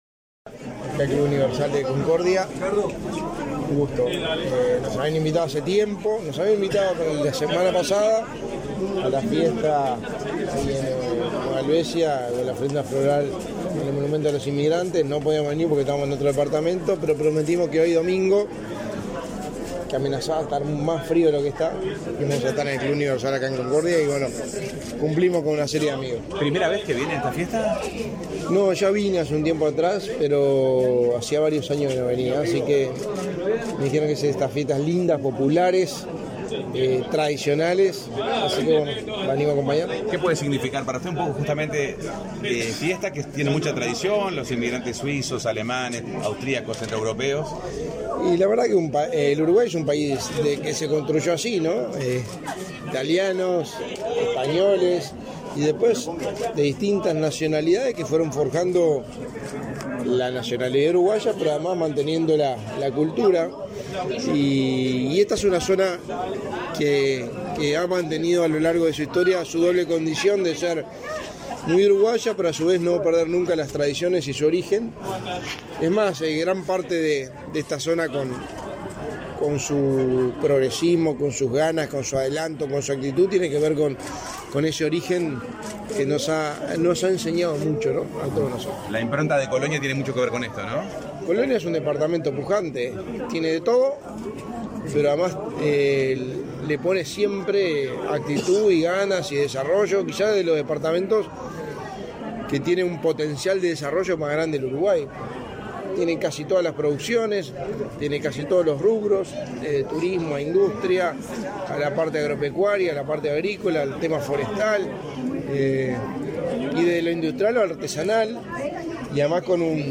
Declaraciones a la prensa del secretario de la Presidencia, Álvaro Delgado
Declaraciones a la prensa del secretario de la Presidencia, Álvaro Delgado 13/08/2023 Compartir Facebook X Copiar enlace WhatsApp LinkedIn En el marco de los festejos del aniversario de la ciudad de Nueva Helvecia, este 13 de agosto, el secretario de la Presidencia de la República, Álvaro Delgado, realizó declaraciones a la prensa.